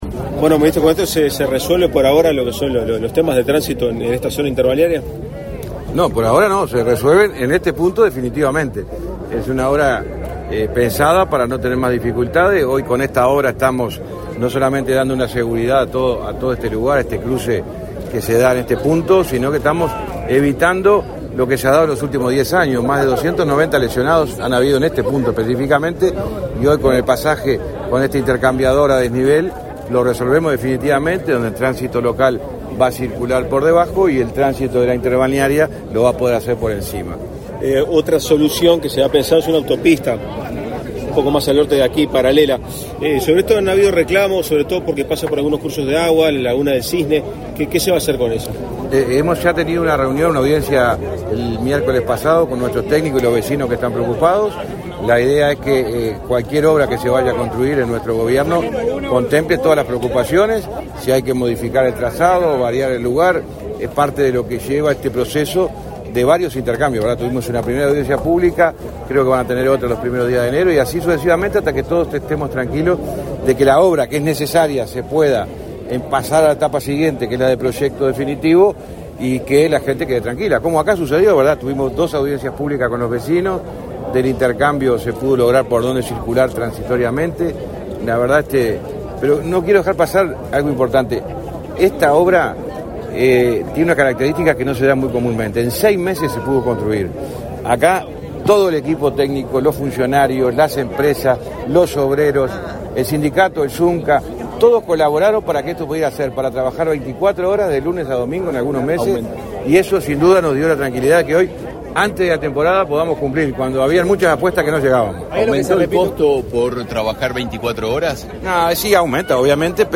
Declaraciones a la prensa del ministro de Transporte y Obras Públicas, José Luis Falero
Declaraciones a la prensa del ministro de Transporte y Obras Públicas, José Luis Falero 22/12/2023 Compartir Facebook X Copiar enlace WhatsApp LinkedIn Con la participación del presidente de la República, Luis Lacalle Pou, se inauguró, este 22 de diciembre, un intercambiador en Parque del Plata. Tras el evento, el ministro de Transporte y Obras Públicas, José Luis Falero, realizó declaraciones a la prensa.